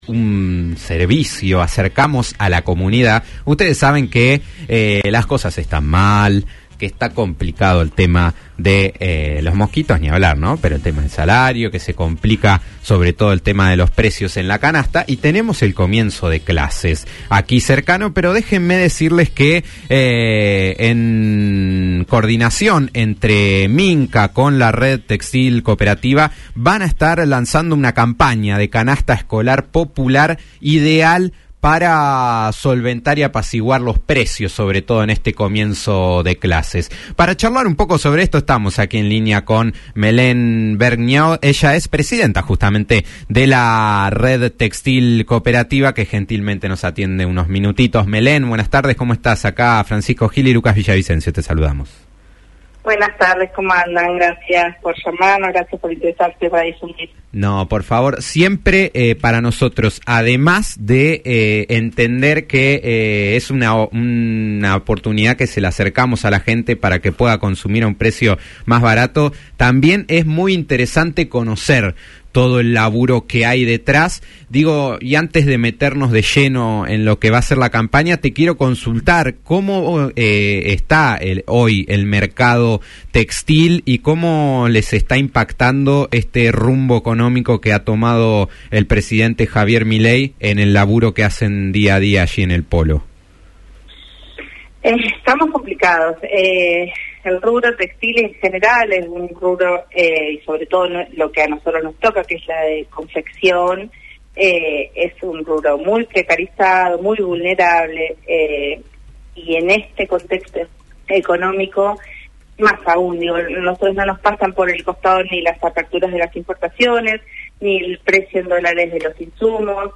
habló sobre esto en Después del Mediodía (fm 90.9)